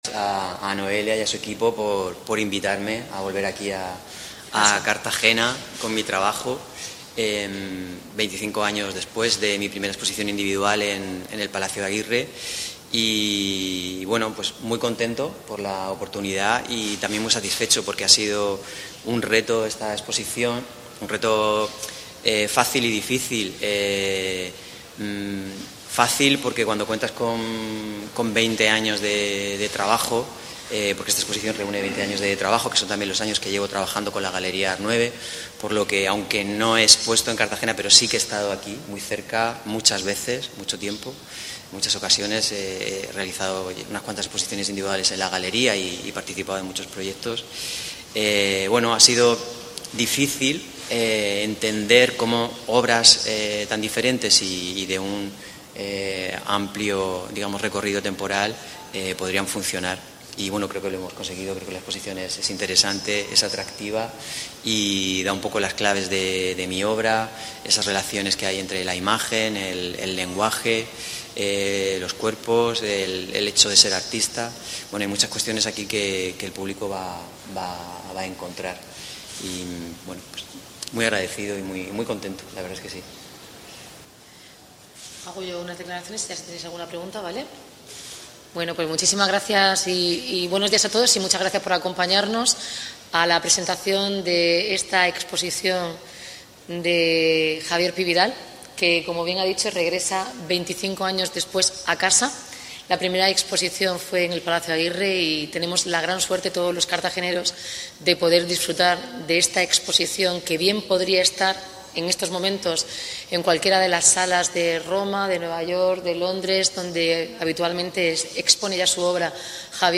Declaraciones de la alcaldesa, Noelia Arroyo